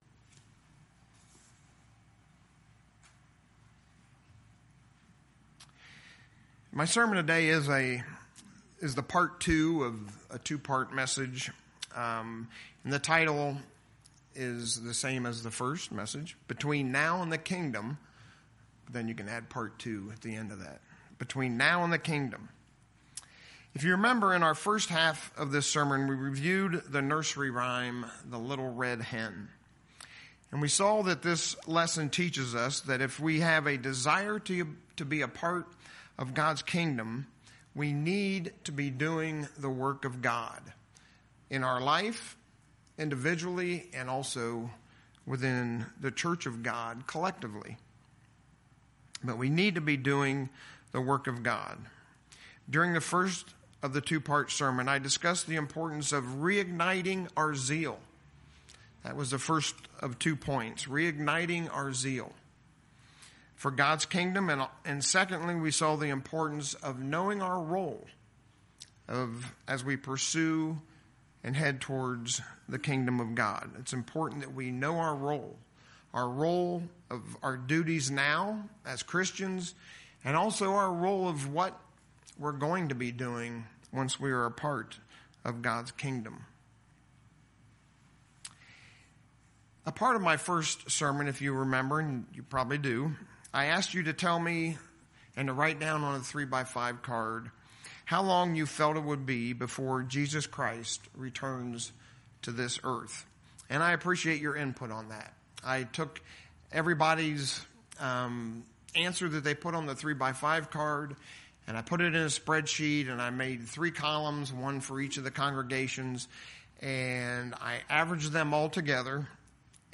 In our sermon today we'll look at Jesus' answer to His disciples question.